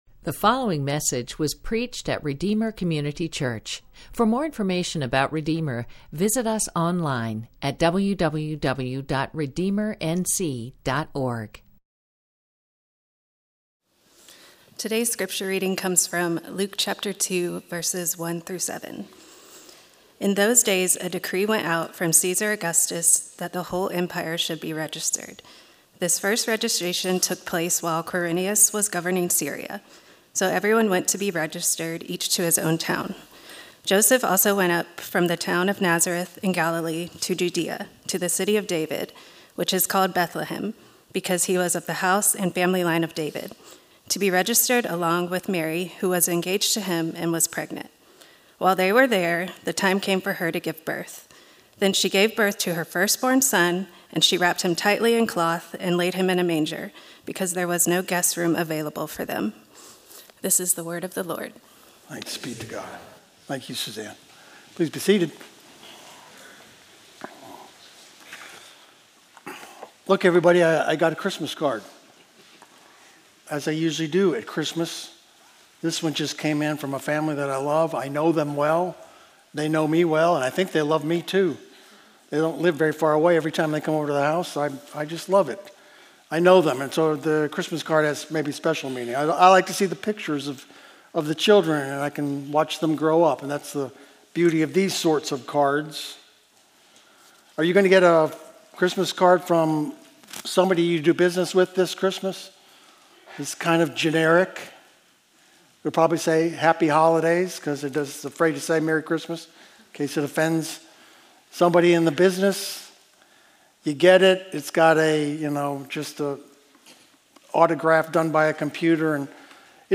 A sermon from the series "Son of the Most High."